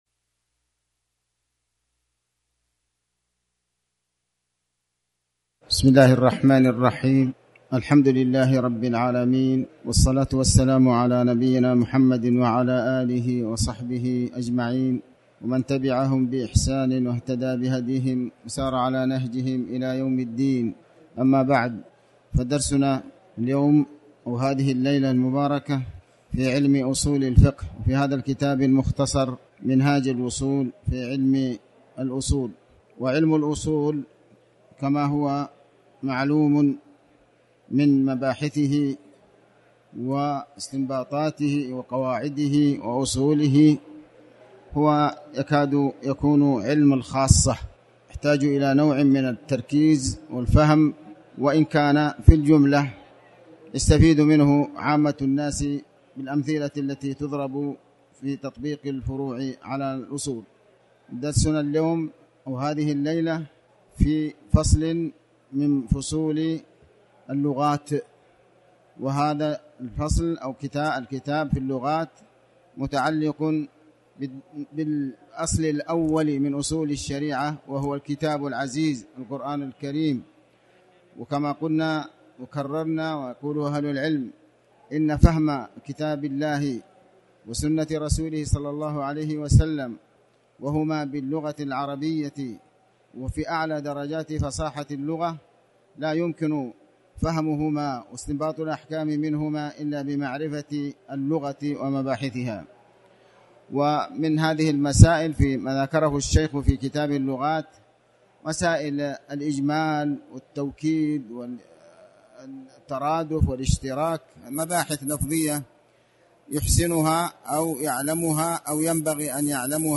تاريخ النشر ١٦ شعبان ١٤٣٩ هـ المكان: المسجد الحرام الشيخ